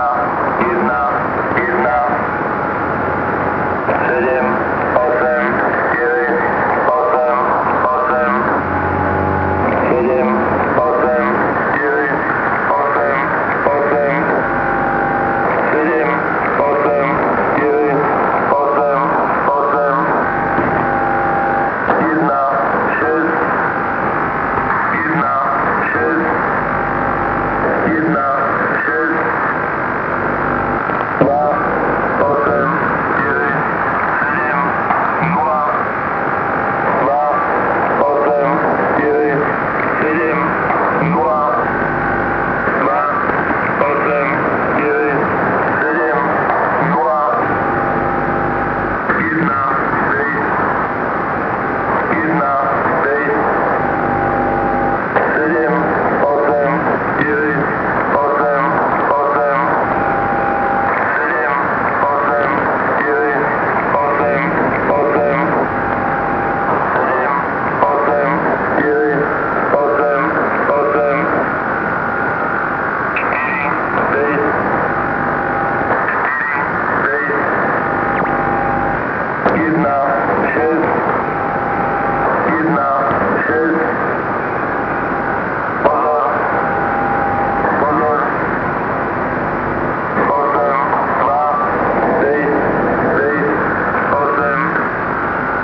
This transmission used 5 figure groups, each repeated, and appeared to have multiple headers.
Closed with 000.
Listen to the station Czech Man (ENIGMA S19A) Recorded August 1, 1998 at 1300 on 10642 khz USB